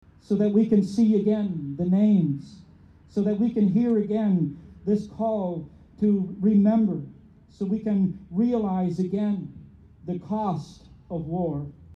Members of the military, special guests, and residents paused Sunday afternoon at the Belleville Cenotaph to commemorate three pivotal battles and occasions in World Wars I and II.